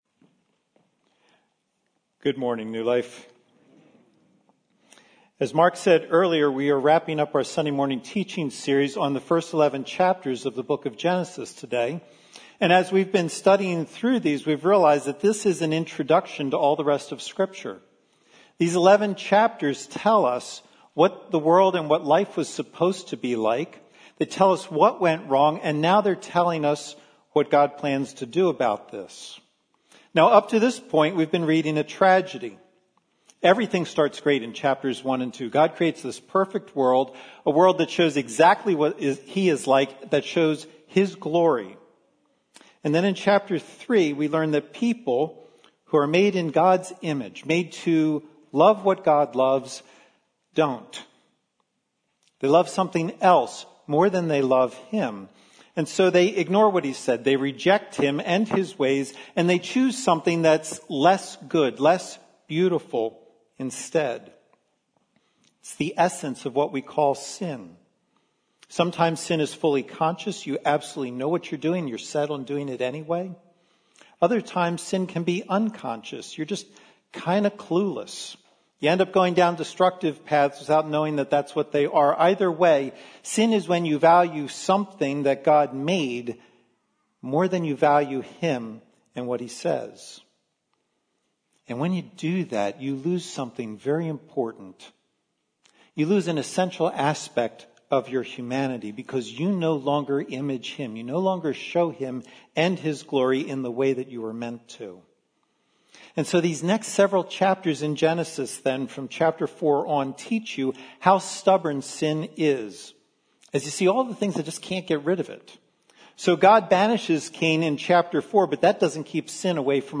Women’s Bible Study – New Life Glenside